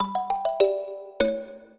mbira
minuet14-5.wav